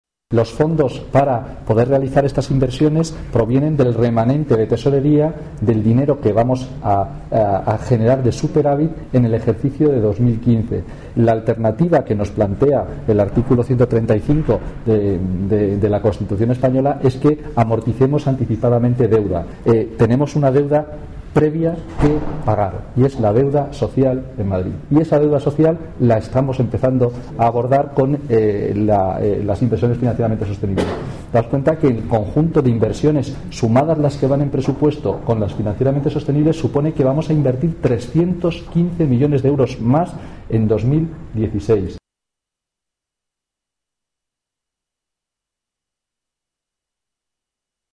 Nueva ventana:Declaraciones de Carlos Sanchez Mato